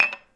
烹饪 " 把玻璃1
Tag: 厨房 桌子 推杆 午餐 玻璃